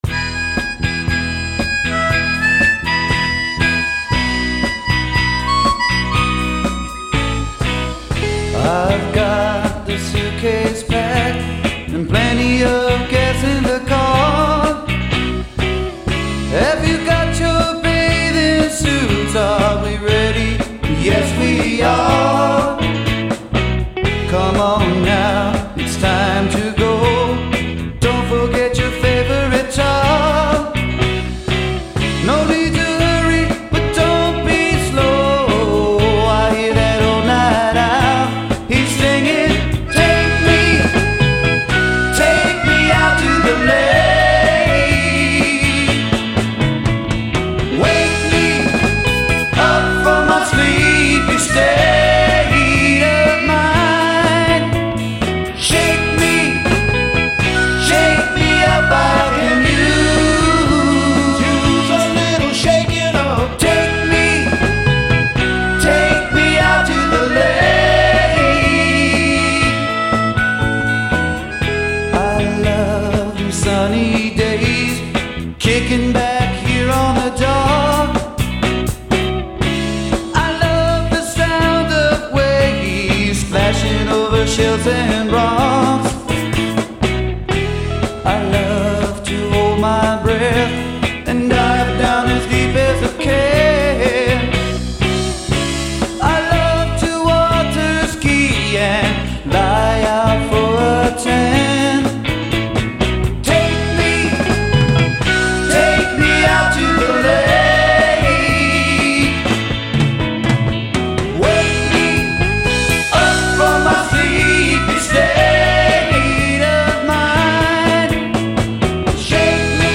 lead vocals